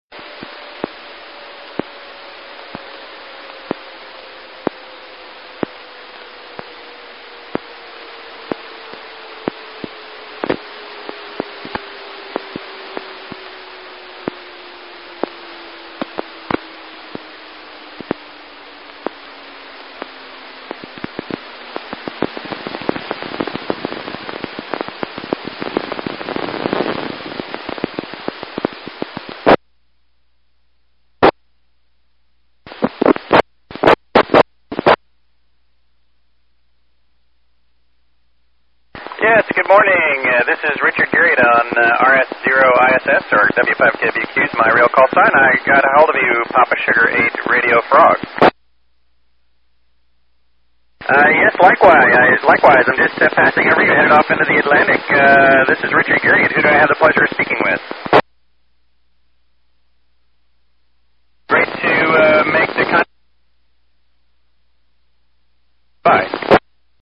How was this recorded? I'm sorry but unfortunately was not possible to record the entire QSO.